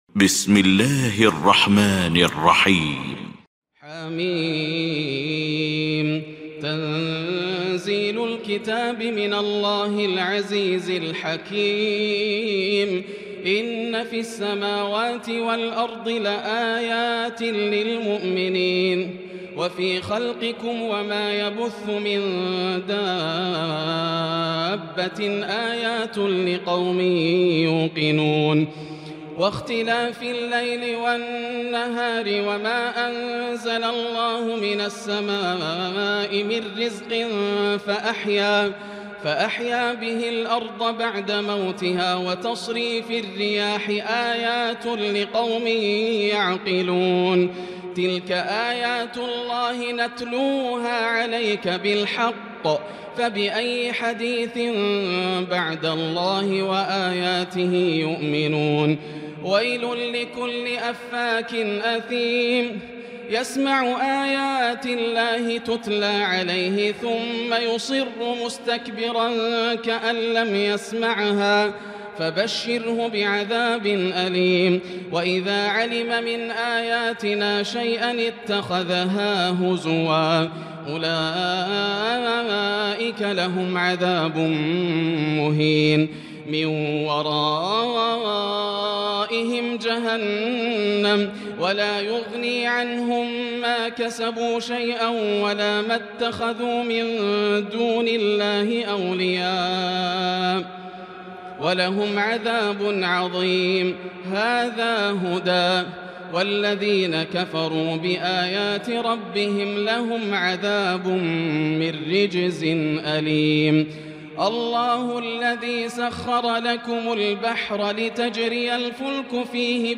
المكان: المسجد الحرام الشيخ: فضيلة الشيخ ياسر الدوسري فضيلة الشيخ ياسر الدوسري الجاثية